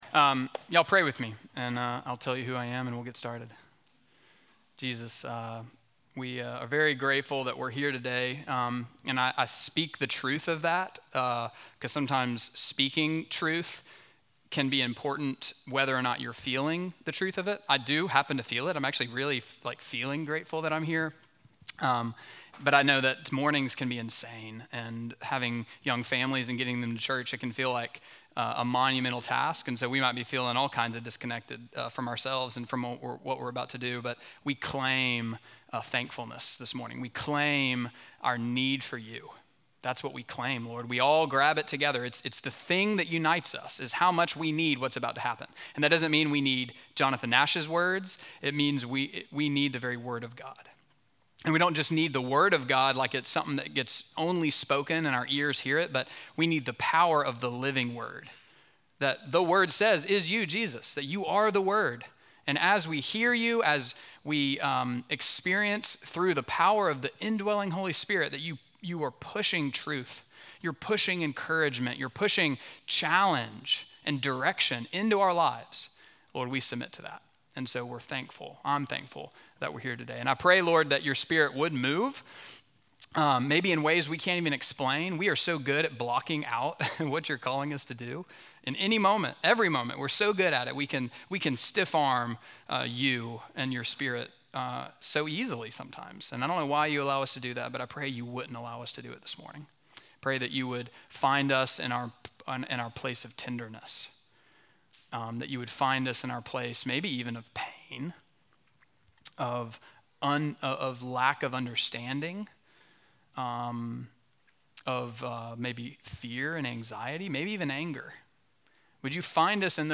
Midtown Fellowship Crieve Hall Sermons Building Beautiful Community Oct 13 2024 | 00:42:32 1x 00:00 / 00:42:32 Subscribe Share Apple Podcasts Spotify Overcast RSS Feed Share Link Embed